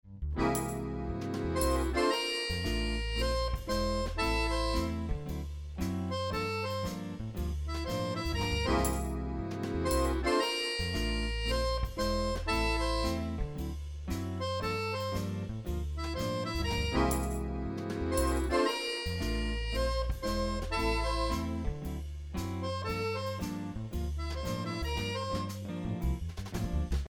stimmungen.mp3